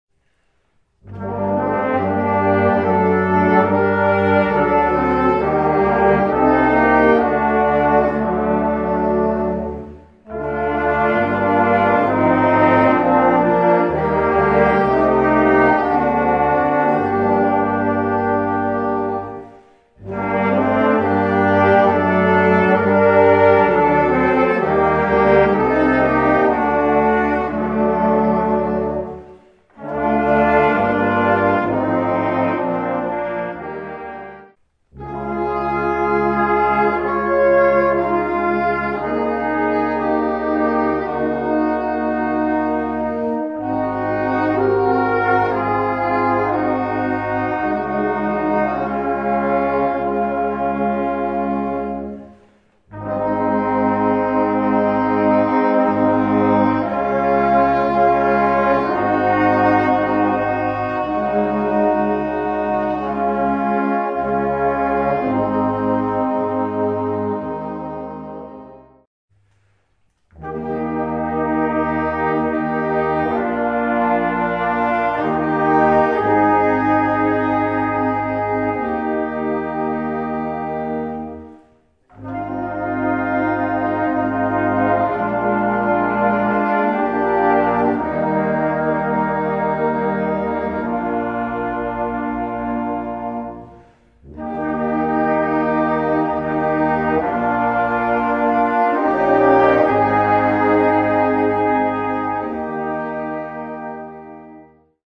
Gattung: Jugendwerk
A4 Besetzung: Blasorchester PDF